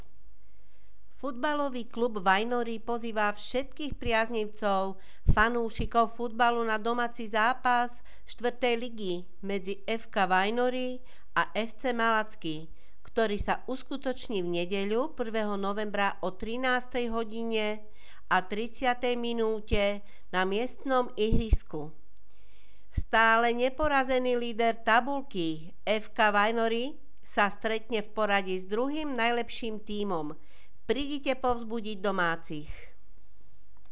Hlásenie miestneho rozhlasu 30.10.2015